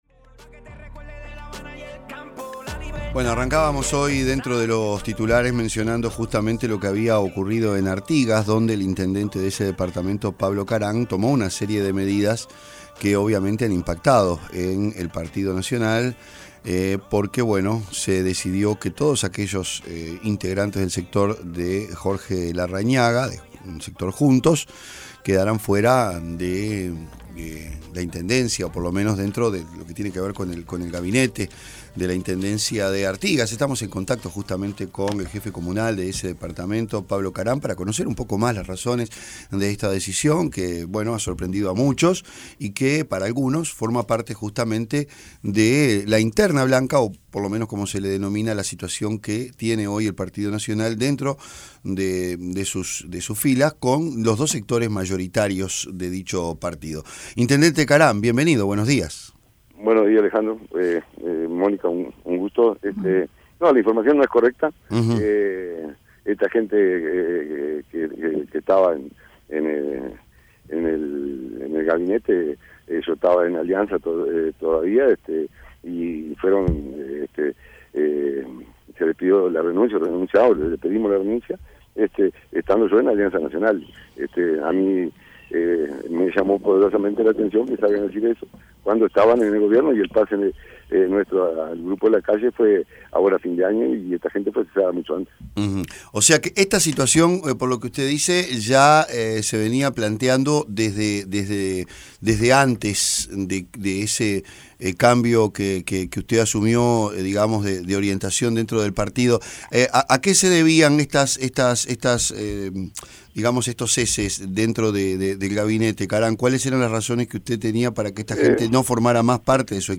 El intendente de Artigas, Pablo Caram, habló en 810 Verano sobre la expulsión de distintos jerarcas del sector de Jorge Larrañaga en la comuna.